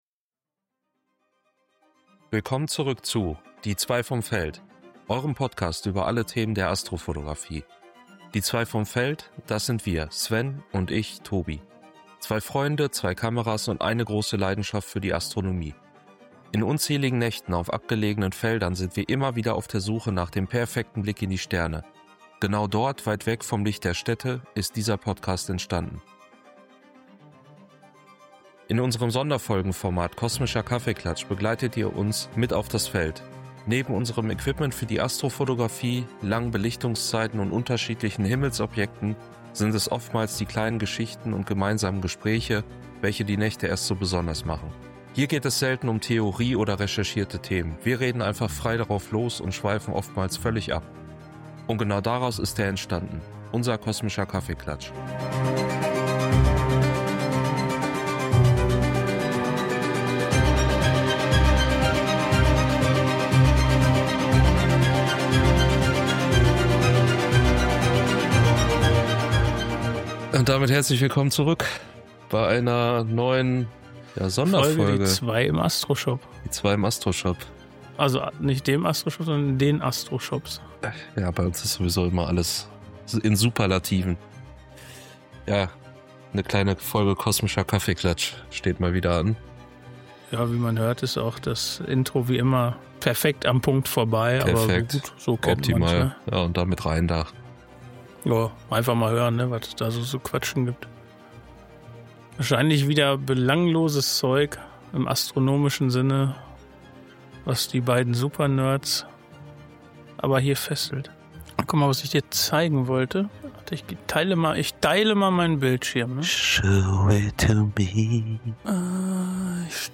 In unserem Sonderfolgenformat Kosmischer Kaffeeklatsch begleitet ihr uns mit auf das Feld.
Hier geht es selten um Theorie oder recherchierte Themen, wir reden einfach frei drauf los und schweifen oftmals völlig ab.